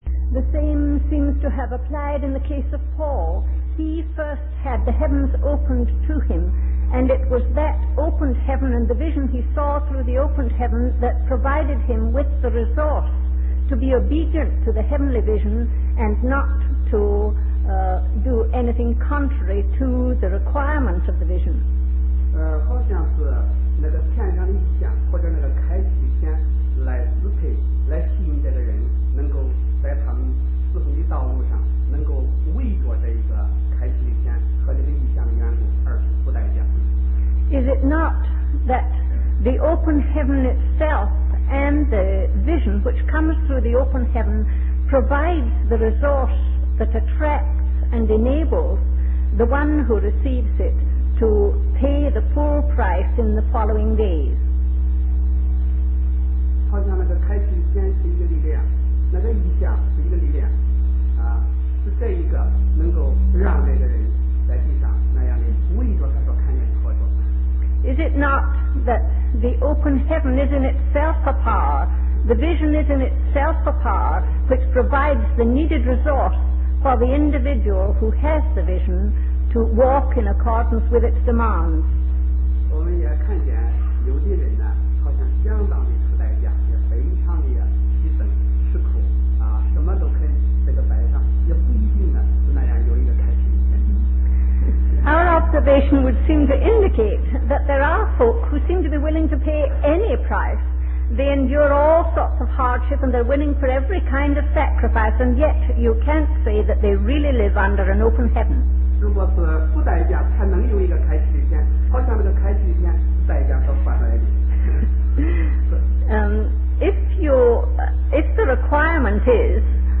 The Persistent Purpose of God #14: Questions and Answers